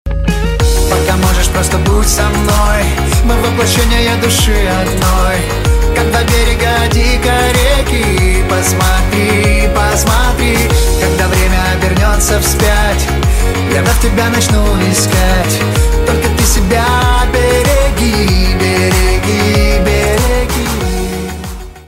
поп
мужской вокал